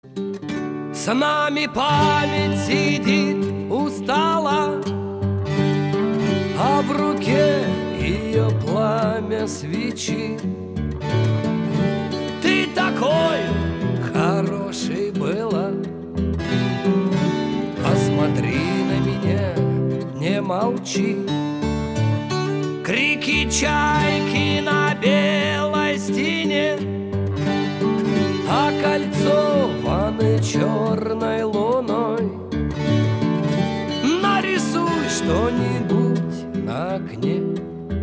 • Качество: 320, Stereo
гитара
душевные
грустные
спокойные
лирические
трогательные
рок-баллада